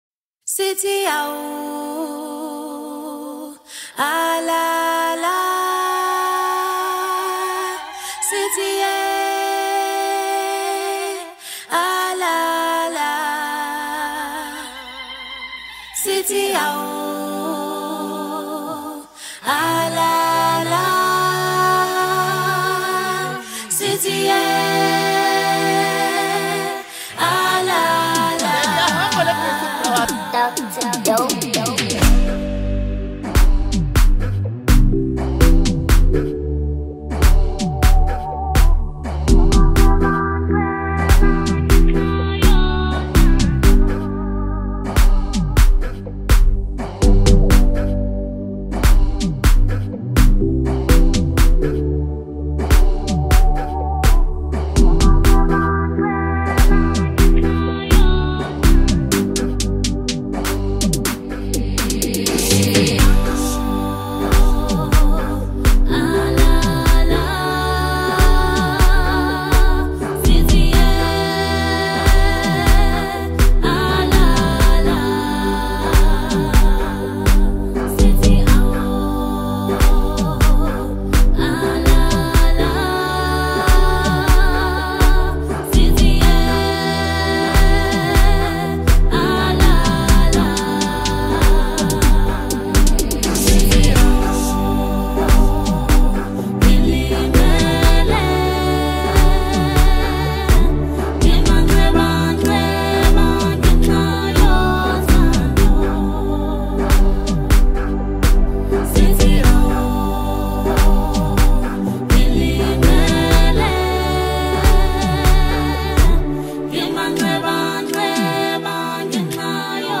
Award winning singer